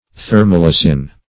Thermolysin \Ther`mo*ly"sin\, n. [Thermo- + Gr. ly`ein to loose